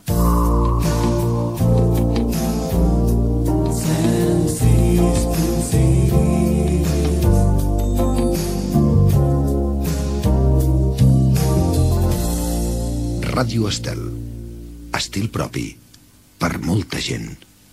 Indicatiu de l' emissora "amb estil propi"